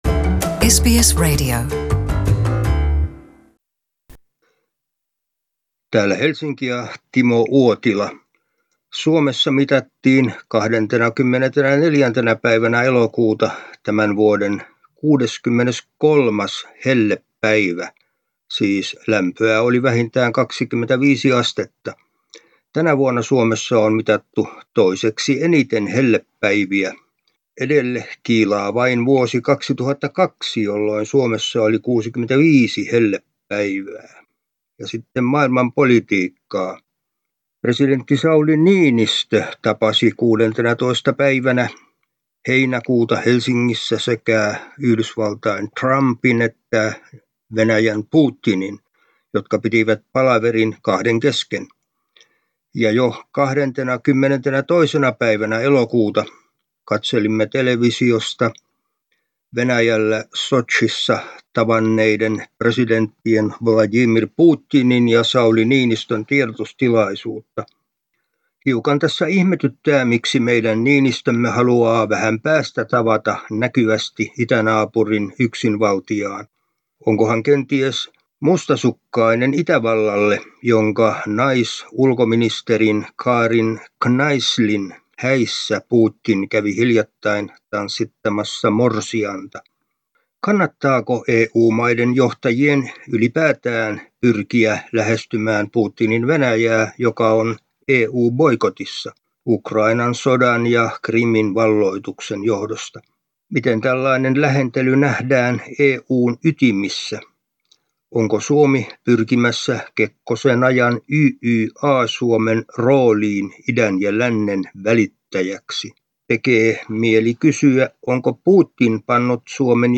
Suomen ajankohtaisraportti